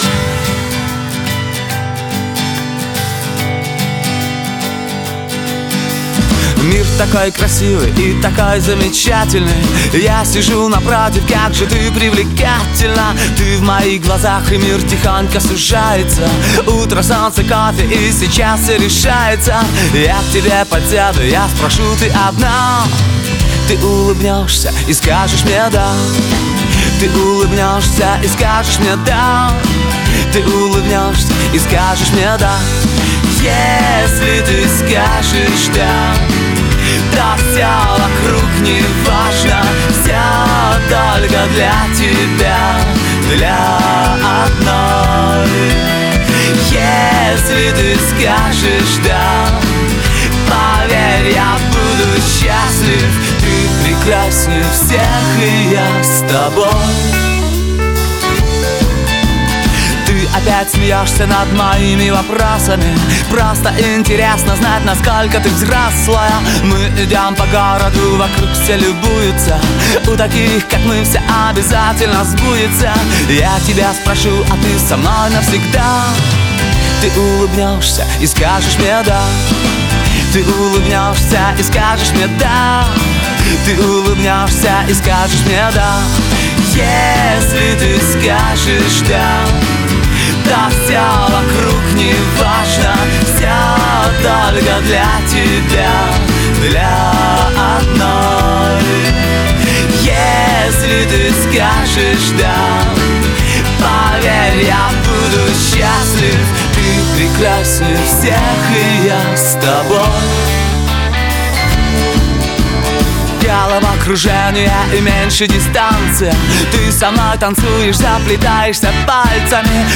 рок-группой